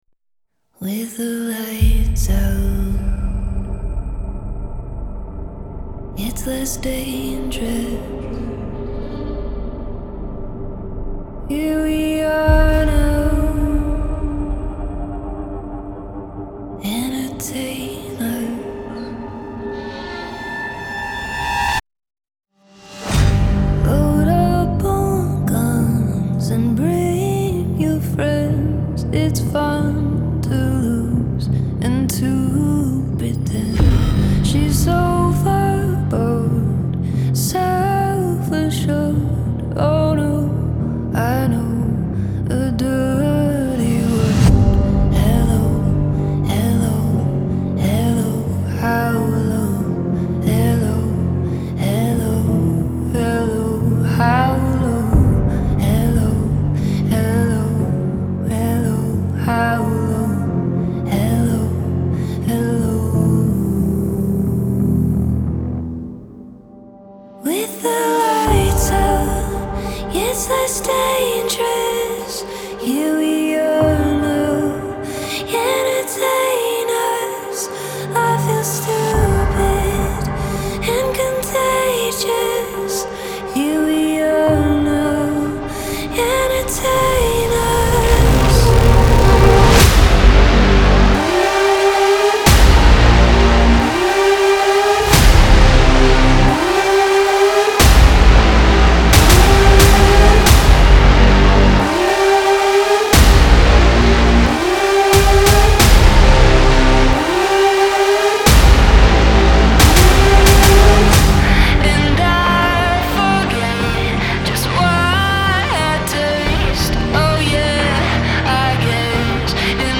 • Жанр: Alternative, Indie